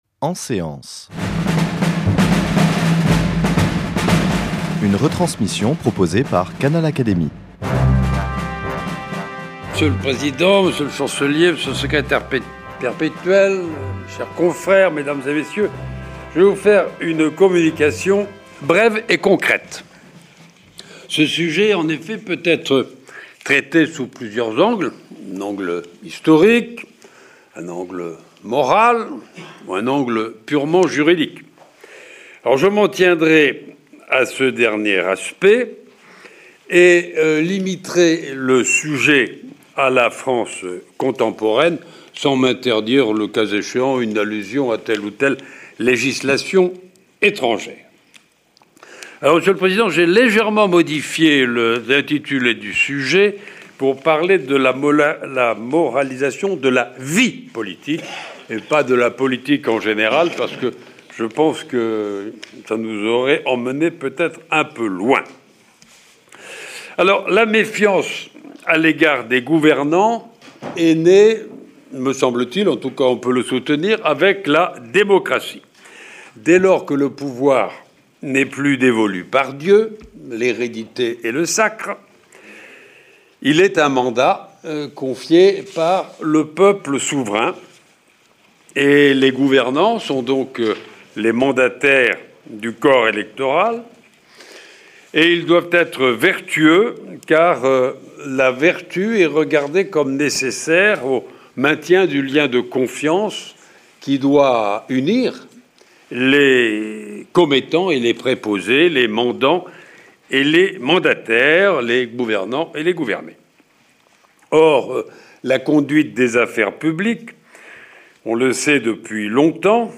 Un exposé limpide en forme de mise en garde.